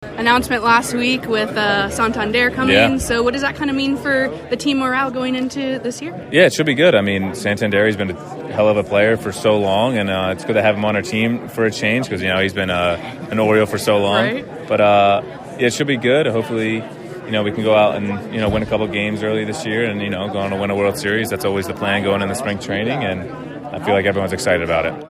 During our interview at the St. Thomas Sports Spectacular back in January, long before the season began- Schneider was already thinking big.